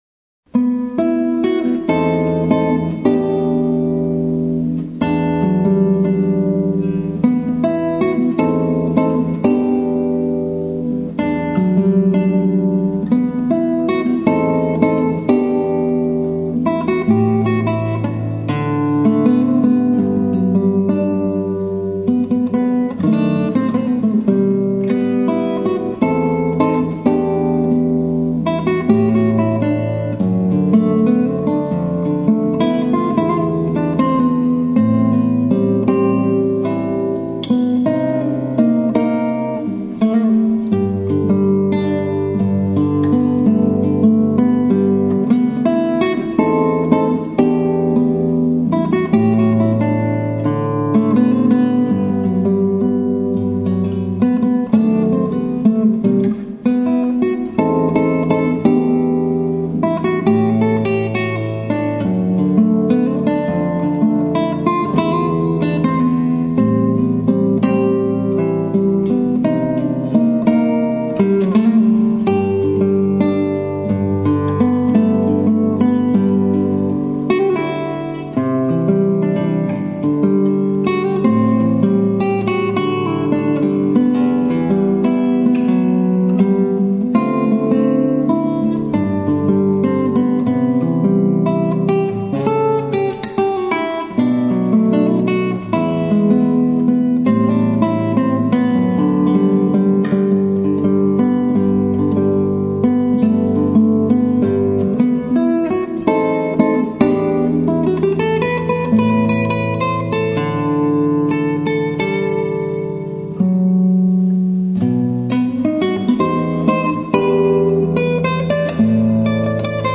Comprendere meglio la musica e suonare meglio la chitarra
in 2 video tutorial in tonalità originale - include assolo
Mio personale arrangiamento